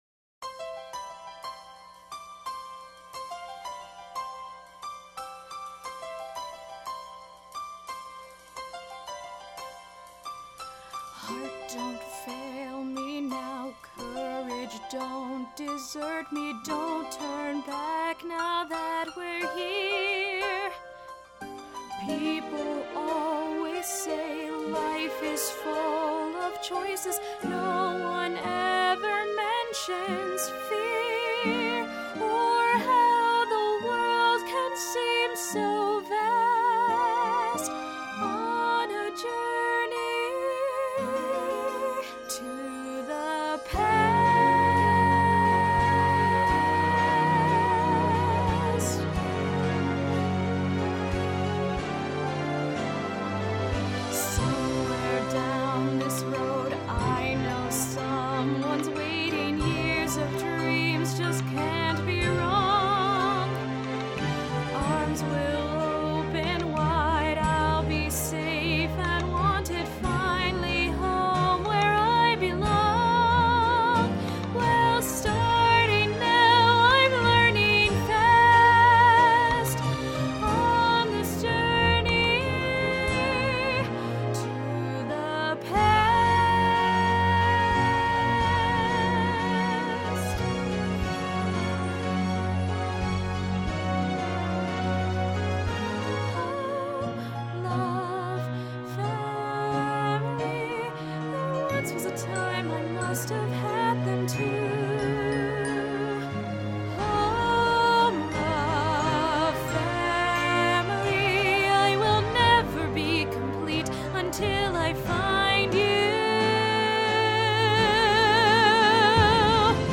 Singing Sample